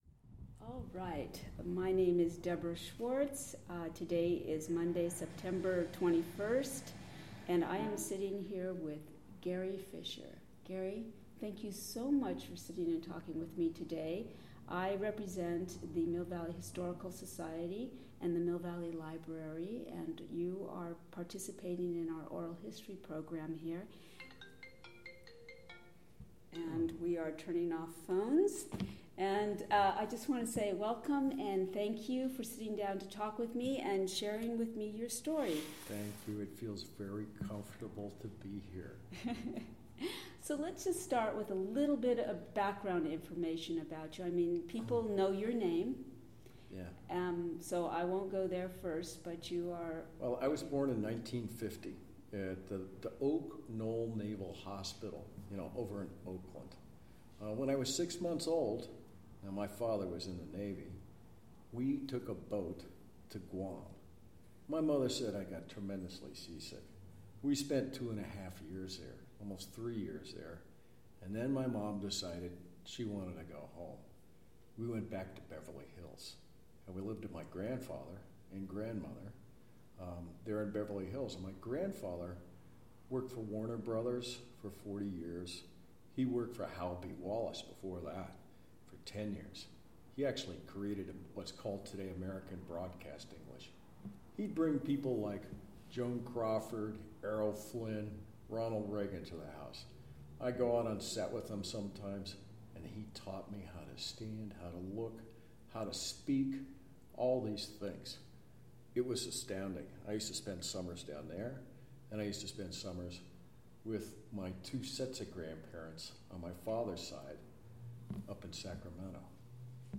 Oral History of Gary Fisher - Oral History | Mill Valley Public Library